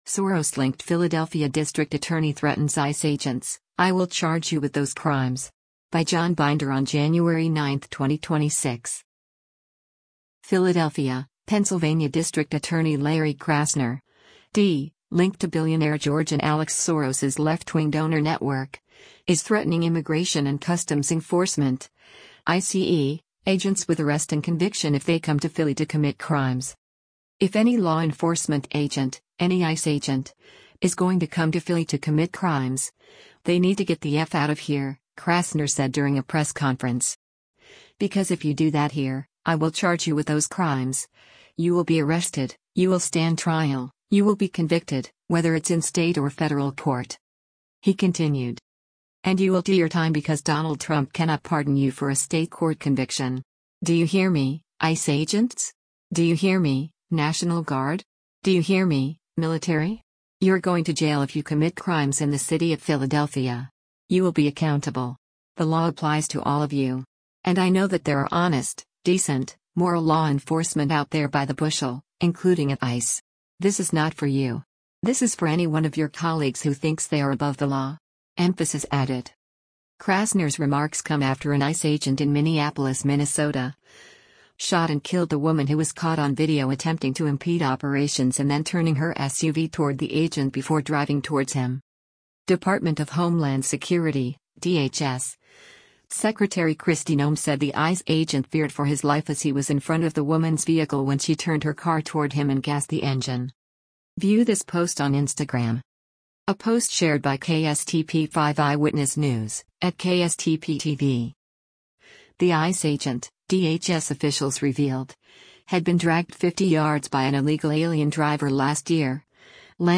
“If any law enforcement agent, any ICE agent, is going to come to Philly to commit crimes, they need to get the eff out of here,” Krasner said during a press conference.